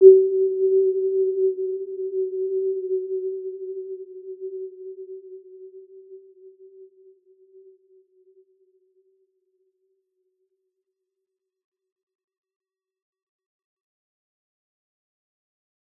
Warm-Bounce-G4-p.wav